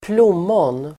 Ladda ner uttalet
Uttal: [²pl'om:ån]